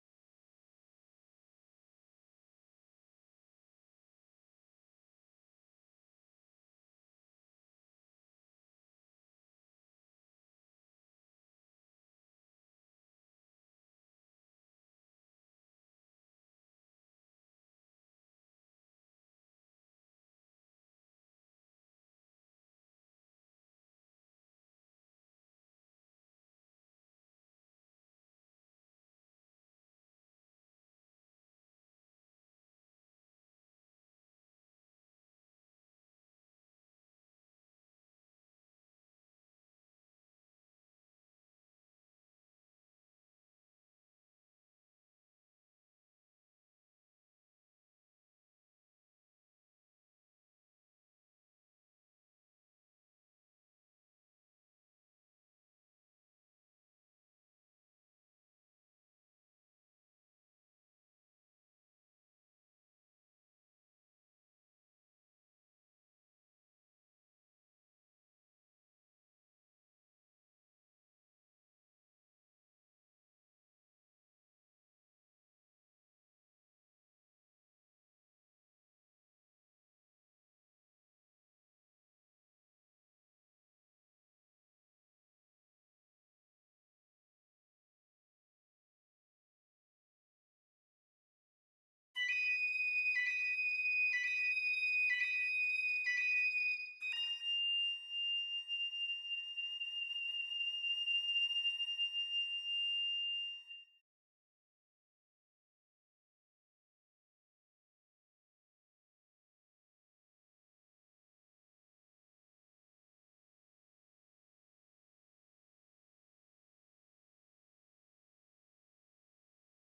1. Piccolo (Piccolo/Normal)
Holst-Mars-29-Piccolo_0.mp3